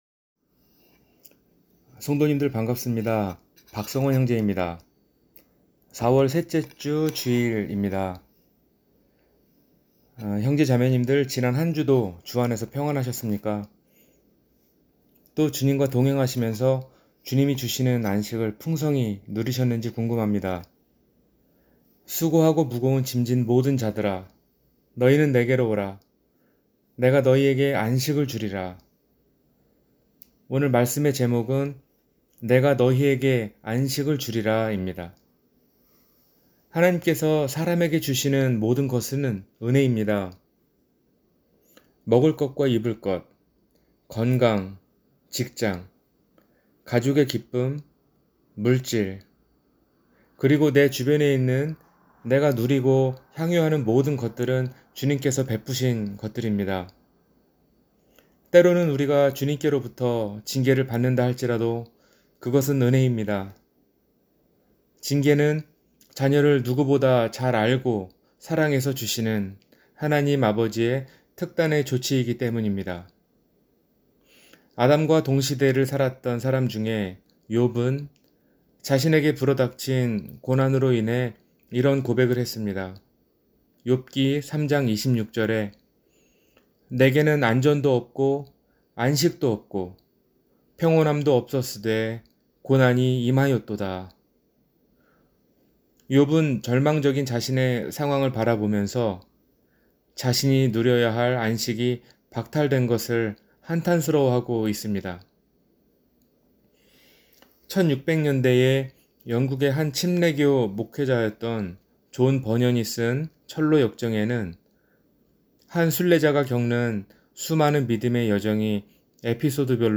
내가 너희에게 안식을 주리라 – 주일설교